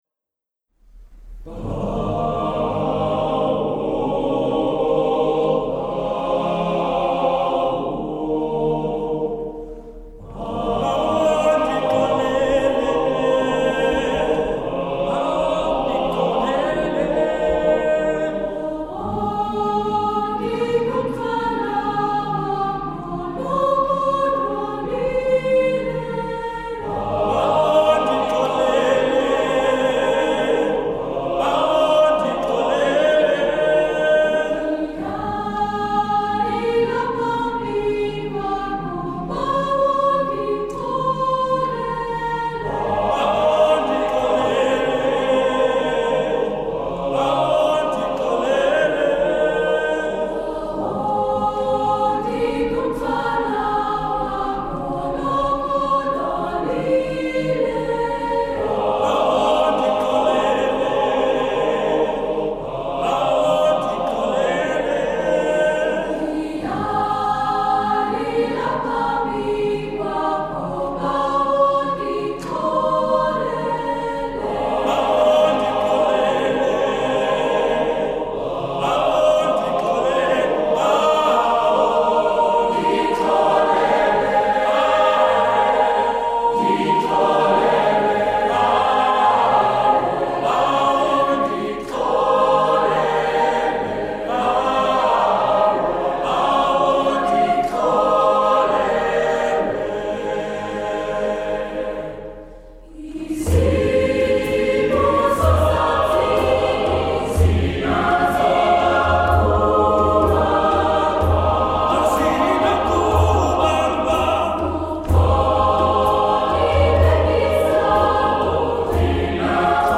Composer: Traditional isiXhosa
Voicing: SATB a cappella